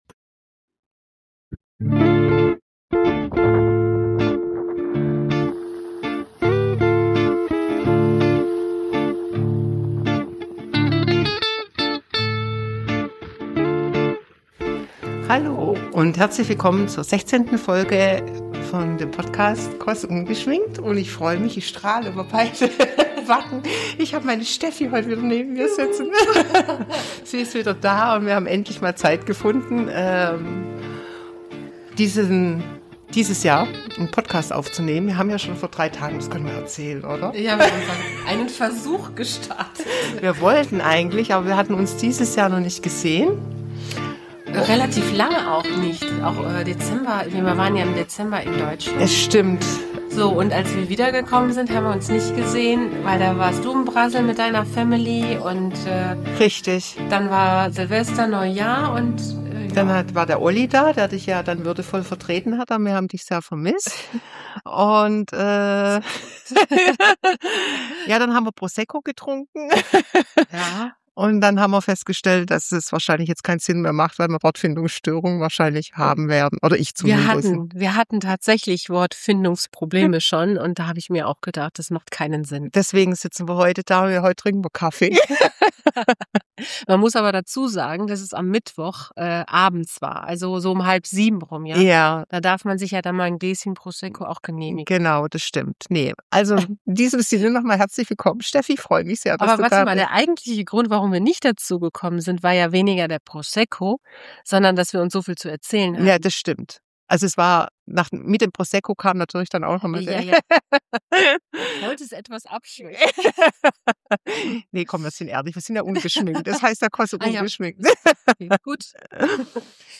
Heute sitzen wir deshalb mit Kaffee zusammen.